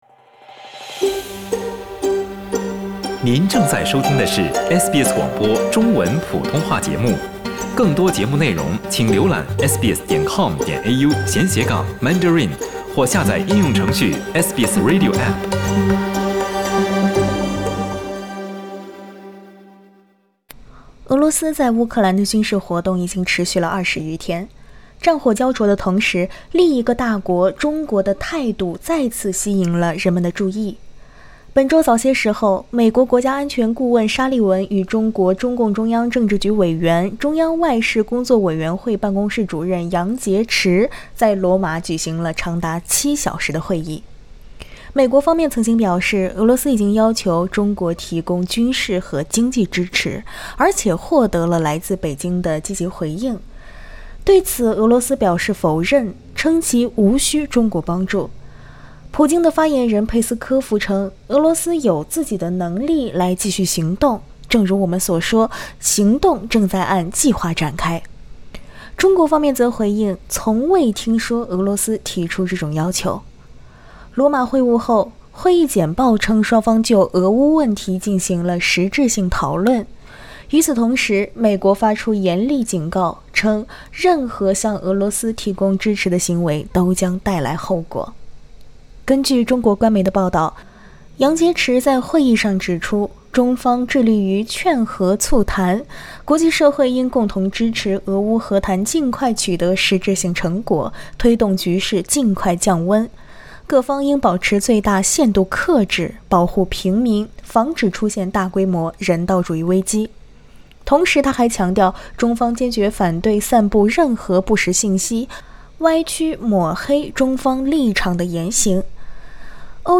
俄罗斯在乌克兰的军事活动已经持续一个月，战火胶着的同时，另一个大国——中国的态度再次吸引了人们的注意。（点击上方图片收听采访）